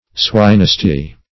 Swinesty \Swine"sty`\